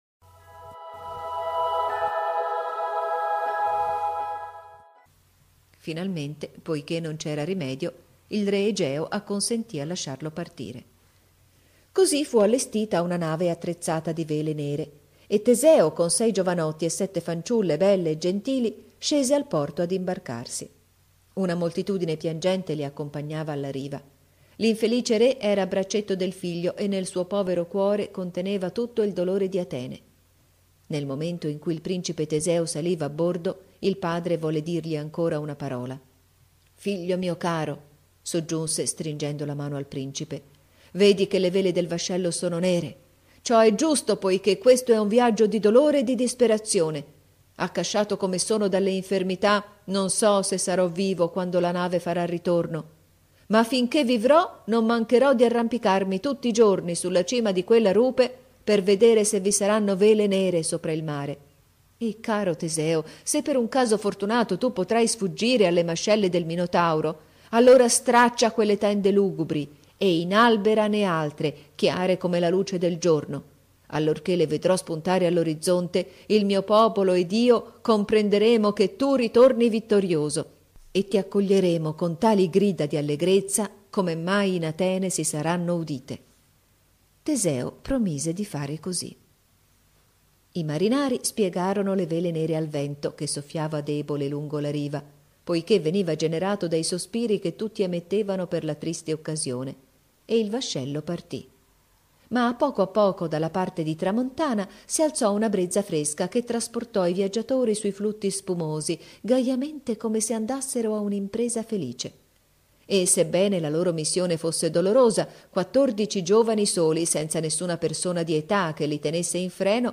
Qui potete riascoltarla, raccontata certo in modo diverso, ma pur sempre lei.